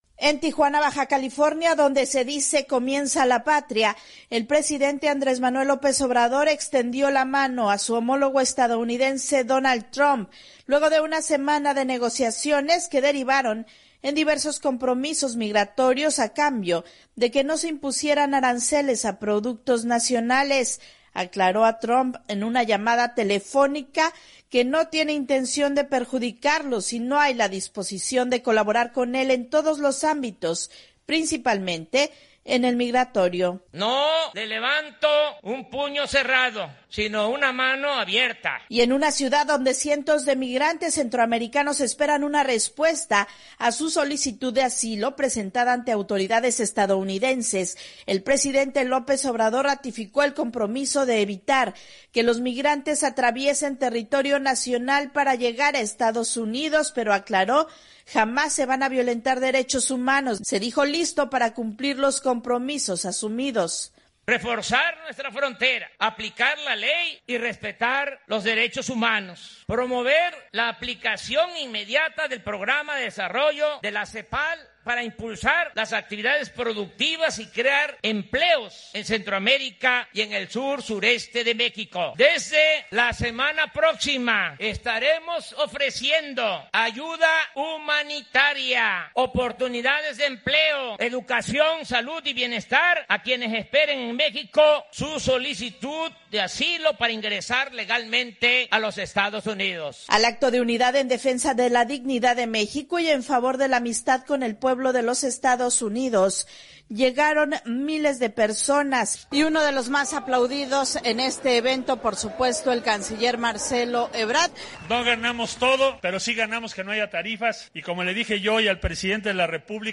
Insertar VOA: Informe desde México Insertar El código se ha copiado en su portapapeles.